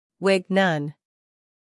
英音/ wɪɡ / 美音/ wɪɡ /